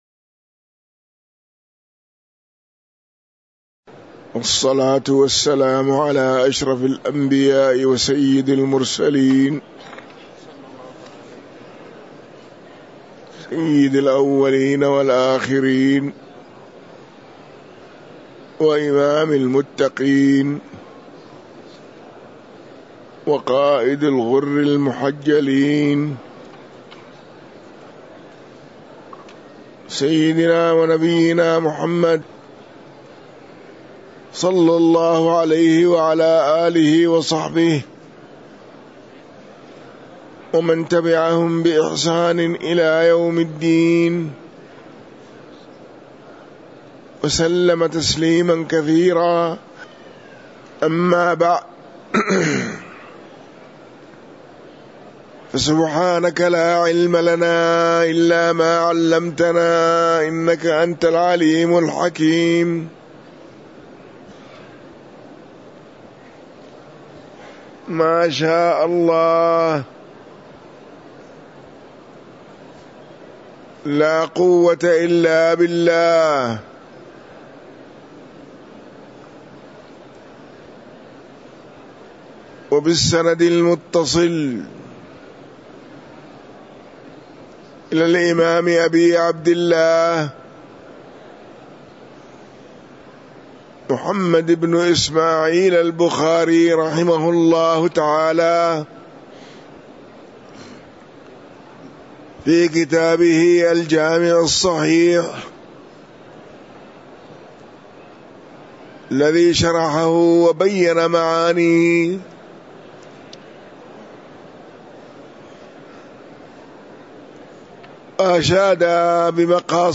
تاريخ النشر ١٨ جمادى الآخرة ١٤٤٤ هـ المكان: المسجد النبوي الشيخ